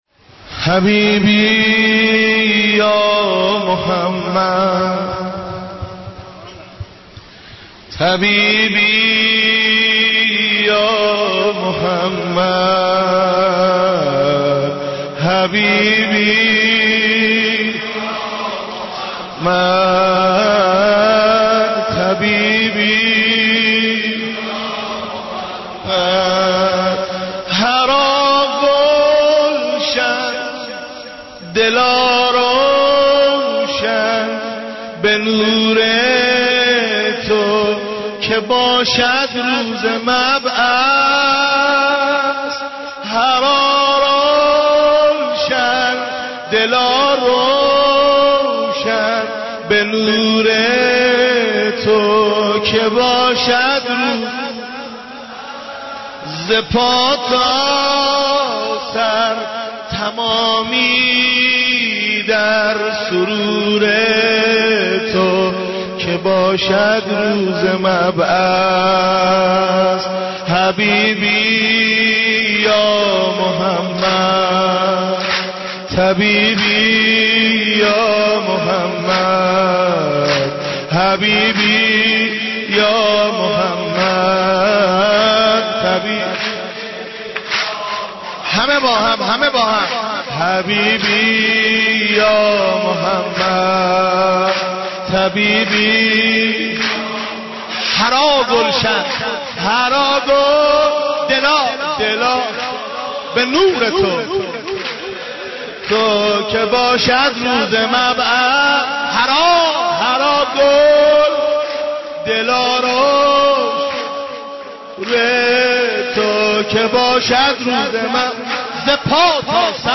گلچین مولودی مبعث پیامبر